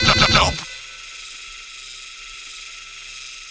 sentry_scan3.wav